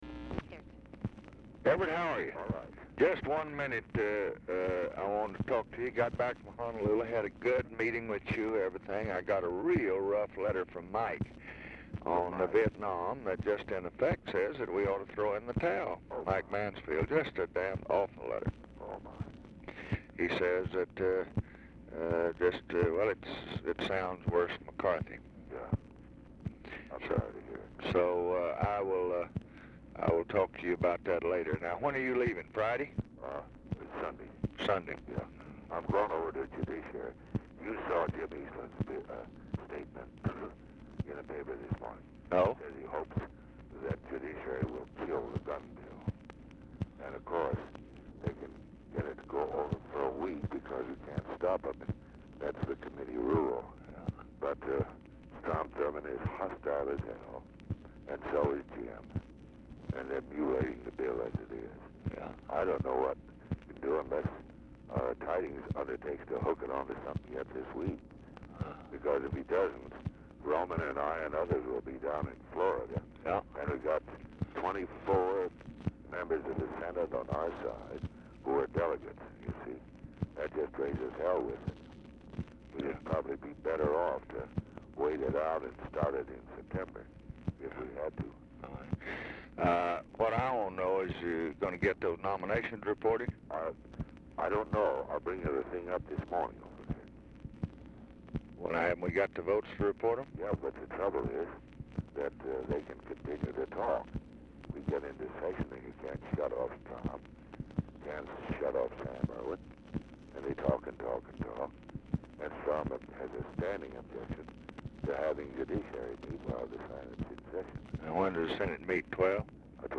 Telephone conversation # 13216, sound recording, LBJ and EVERETT DIRKSEN, 7/24/1968, 10:15AM | Discover LBJ
Format Dictation belt
Location Of Speaker 1 Mansion, White House, Washington, DC
Specific Item Type Telephone conversation